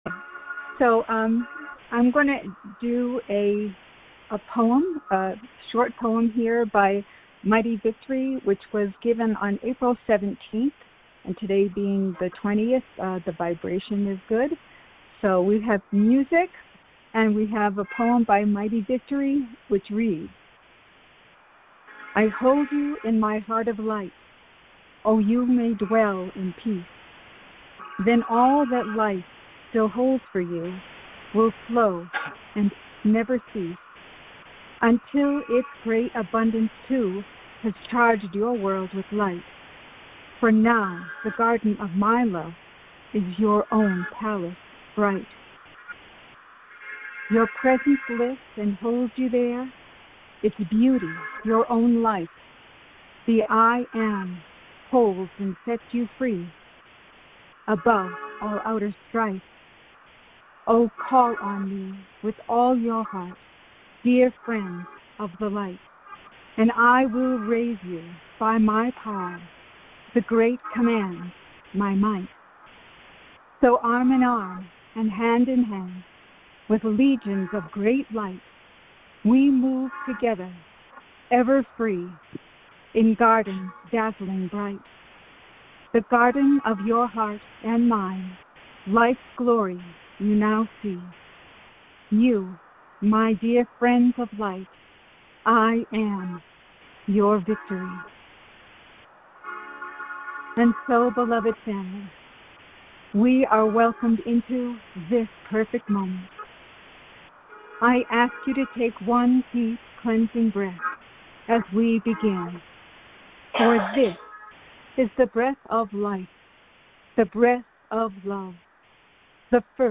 Join in group meditation with master Yeshua (Jesus).